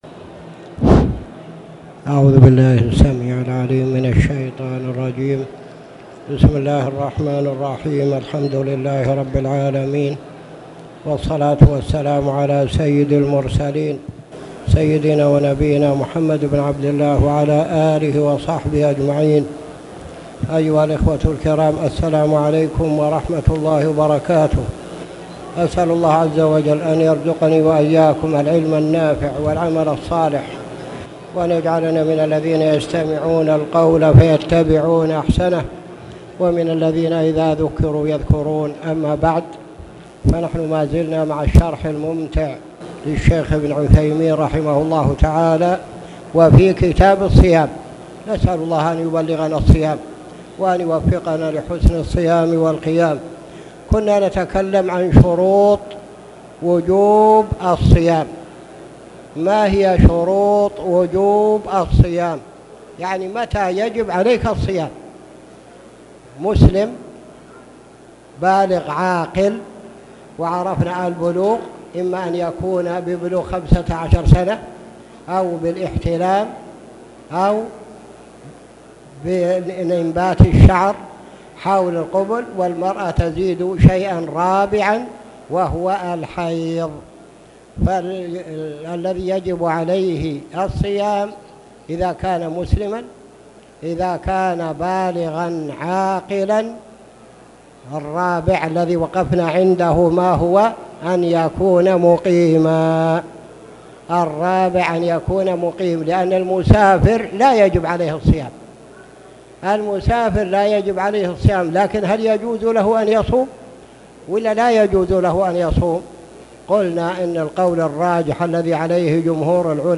تاريخ النشر ٢٠ رجب ١٤٣٨ هـ المكان: المسجد الحرام الشيخ